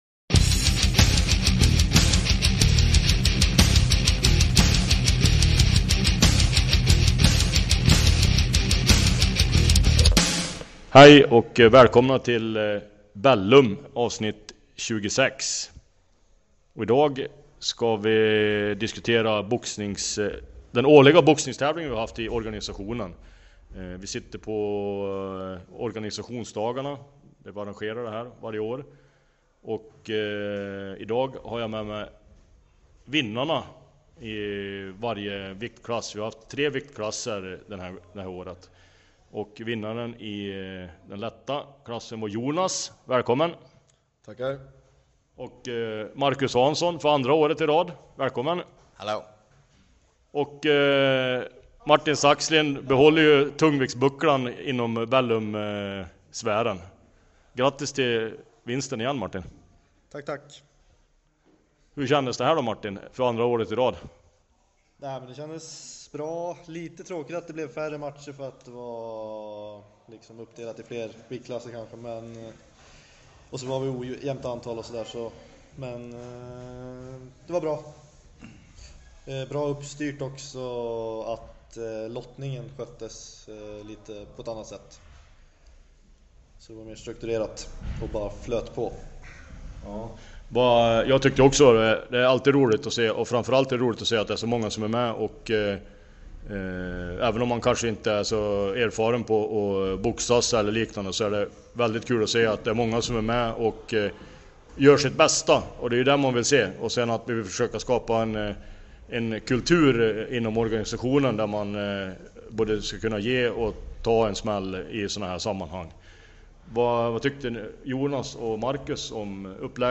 Fältinspelning från Organisationsdagarna med vinnarna i alla tre viktklasser i boxningsturneringen.